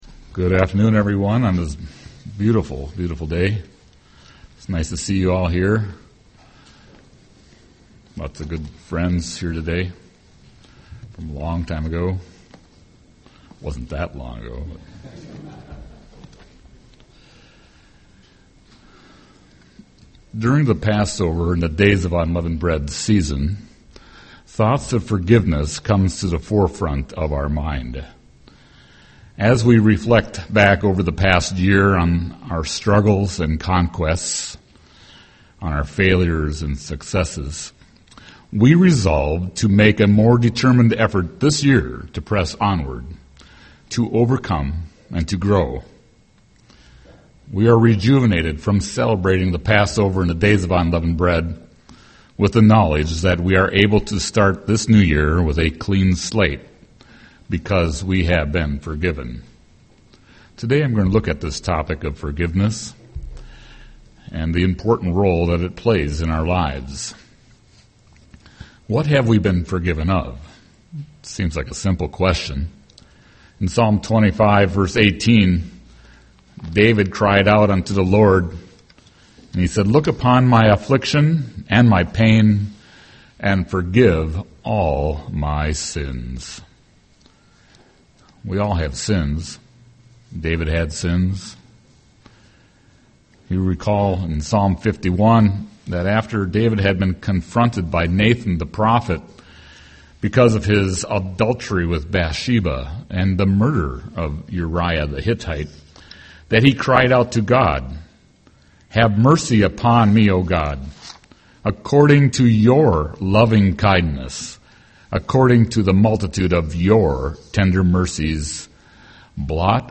Given in Twin Cities, MN
UCG Sermon Forgiveness Studying the bible?